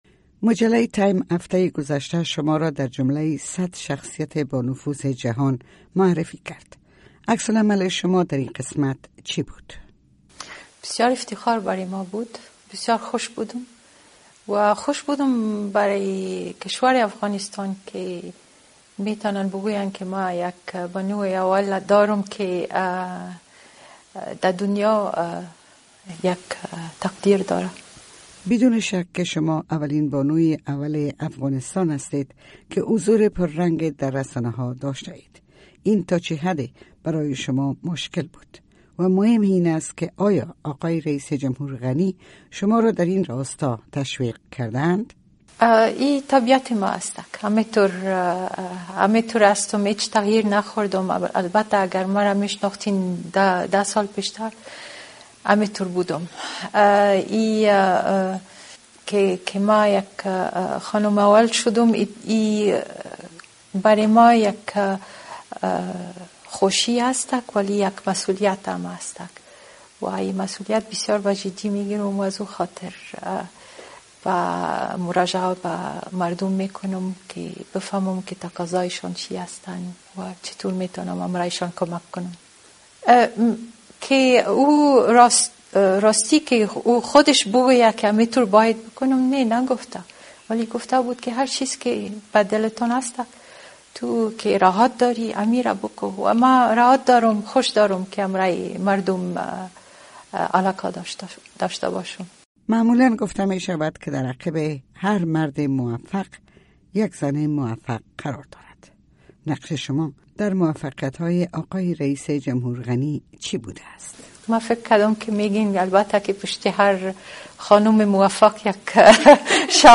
مصاحبه با رولا غنی همسر رییس جمهور افغانستان
مصاحبه تلویزیون رادیو صدای امریکا با رولا غنی همسر رییس جمهورافغانستان